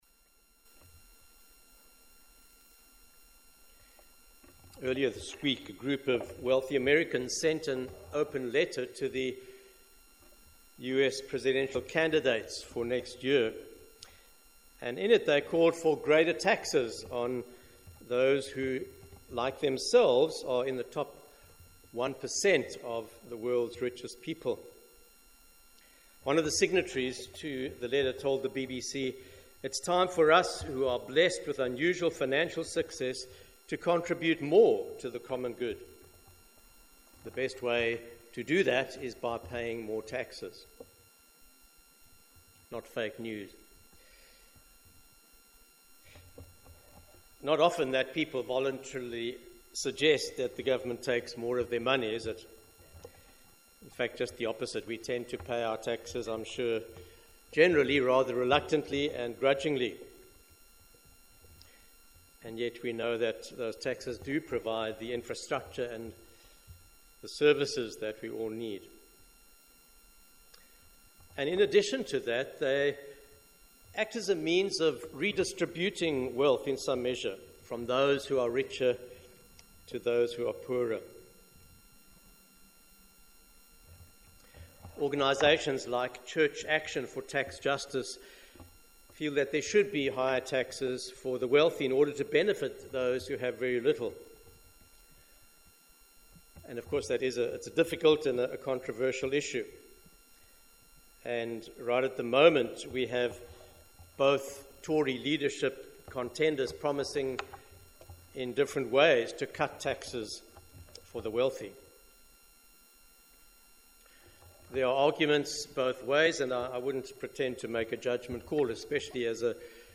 Sermons at LMC